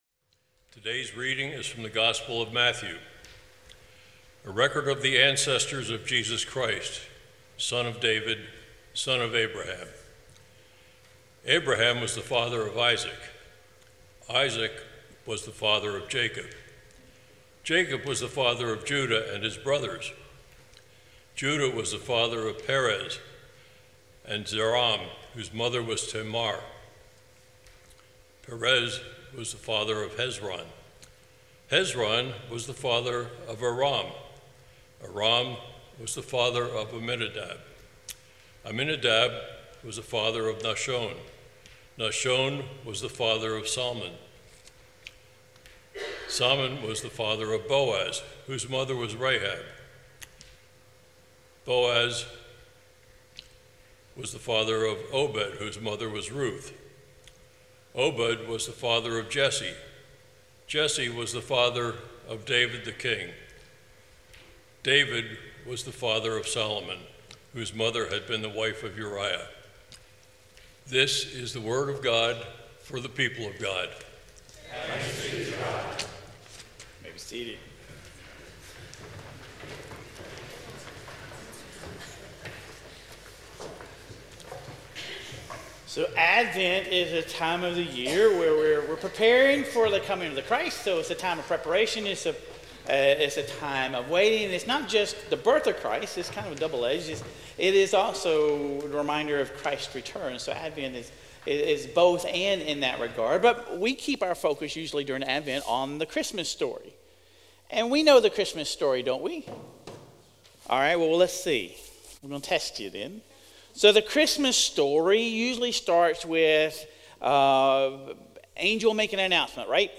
Sermon Reflections: